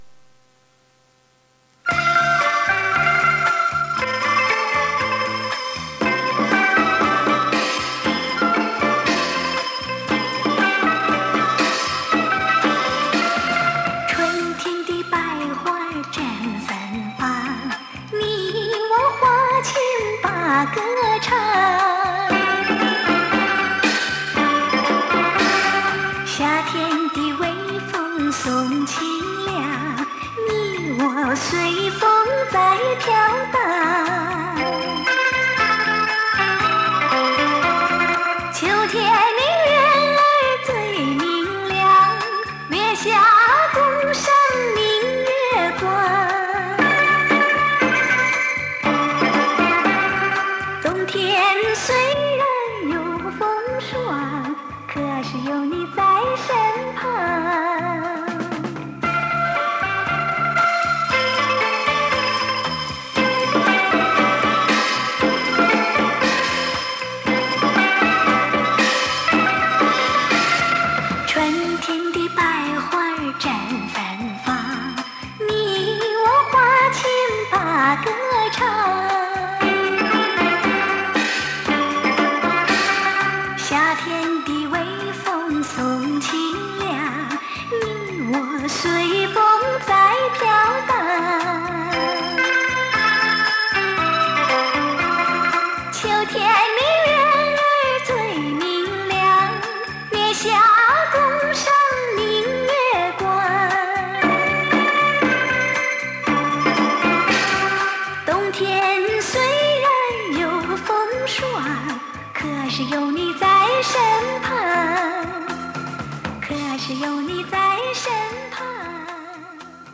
磁带数字化：2022-08-21
温馨初恋情怀